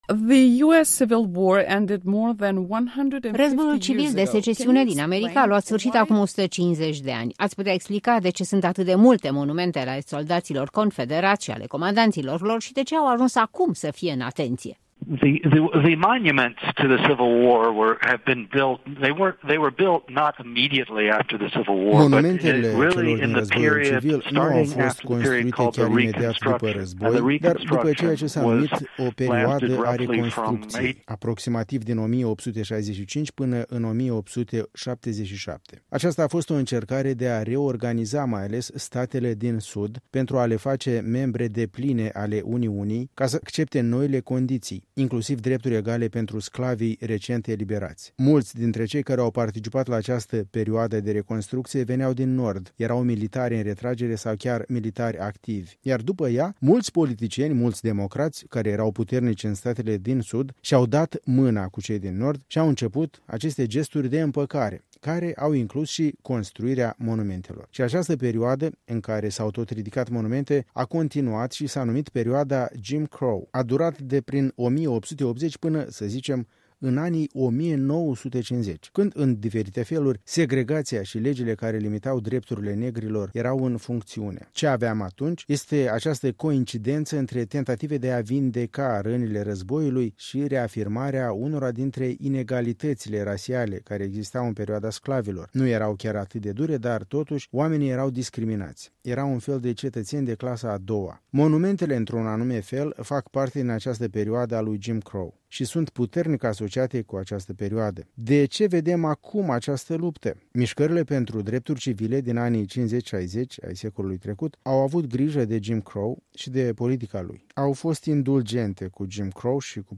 Un interviu cu analistul politic american